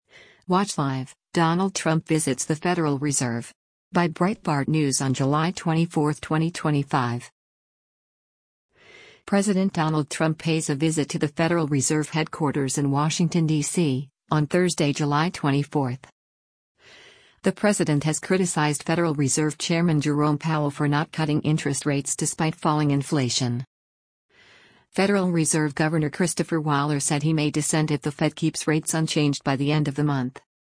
President Donald Trump pays a visit to the Federal Reserve headquarters in Washington, DC, on Thursday, July 24.